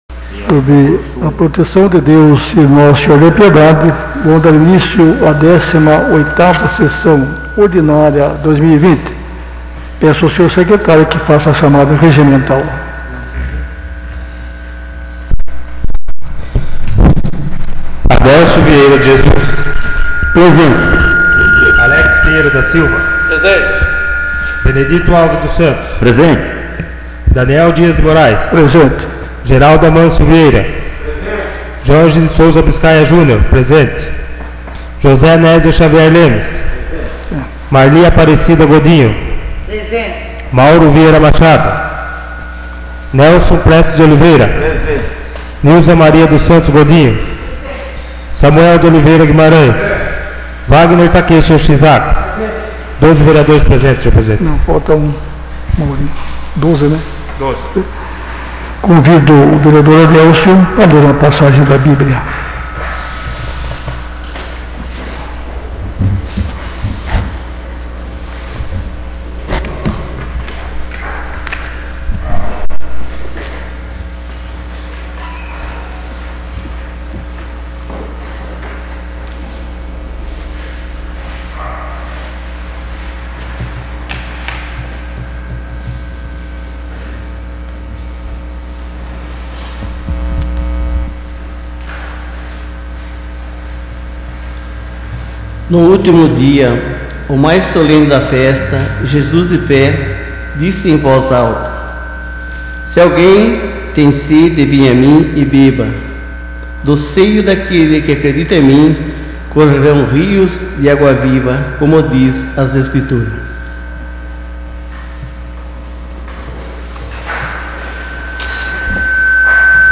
18ª Sessão Ordinária de 2020